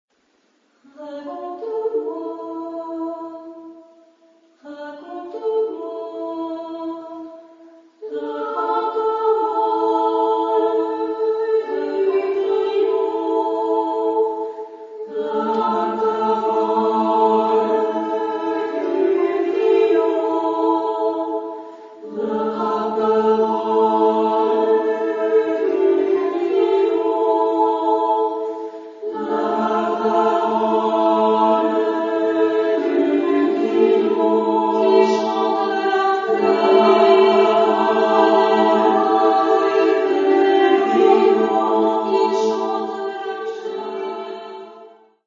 Genre-Style-Forme : Profane ; Poème ; contemporain
Type de choeur : SSMMAA  (6 voix égales de femmes )
Tonalité : ré (centré autour de)